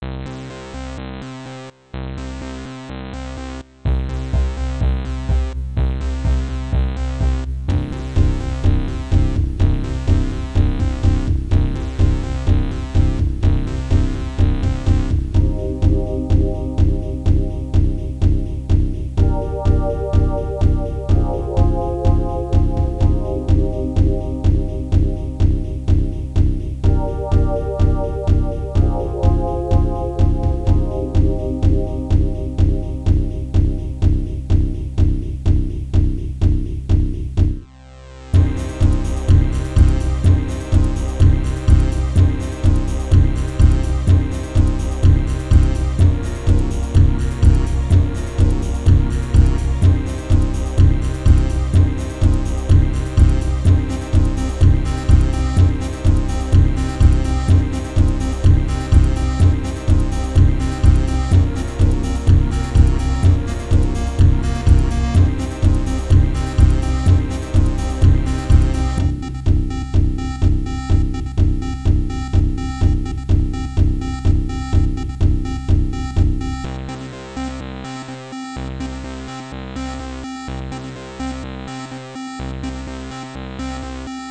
Oldschool Amiga MOD
• Music is loop-able, but also has an ending